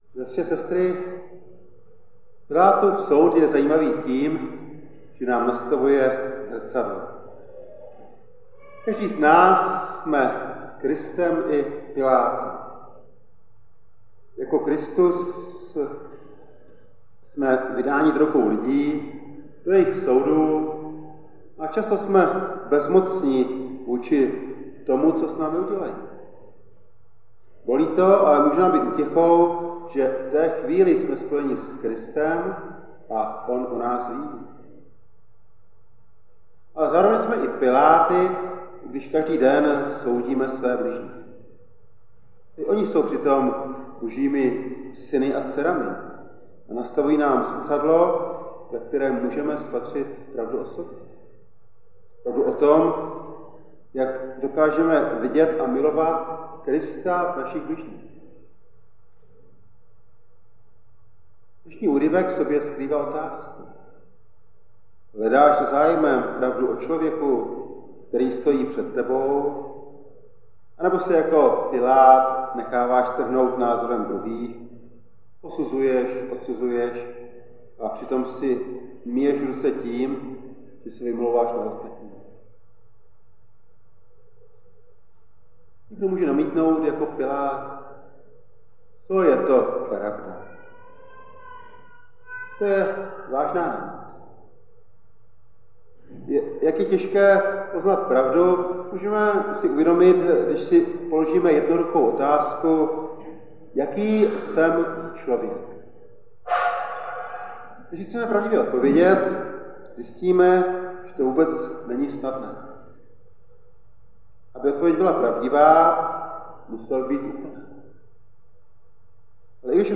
Audio Kázání z Vranova nad Dyjí 24.11.2024 (slavnost Krista Krále)